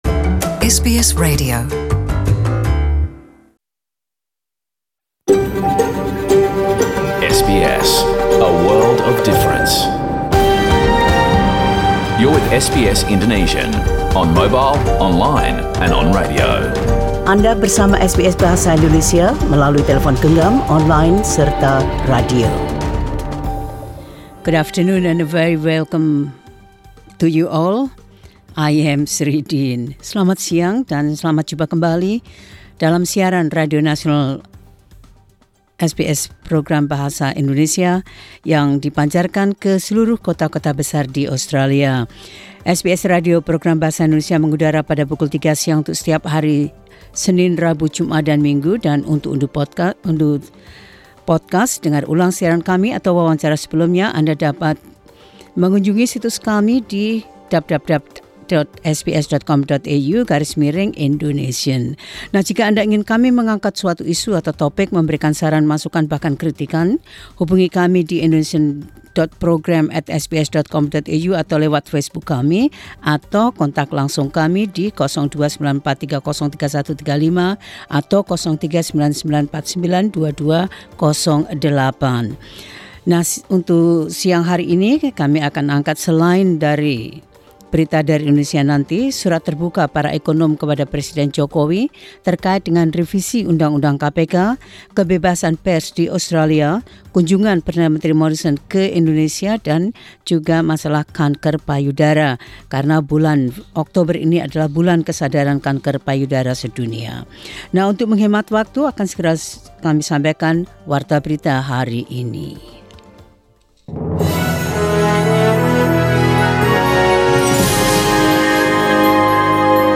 Warta Berita Radio SBS dalam Bahasa Indonesia 21 Okt 2019.
SBS Radio News in Indonesian 21 Oct 2019.